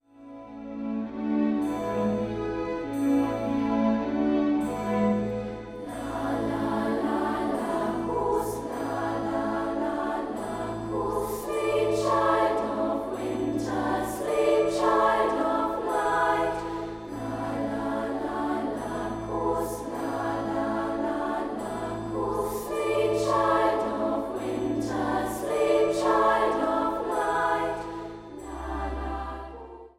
Klavier
einfühlsame Klavierbegleitung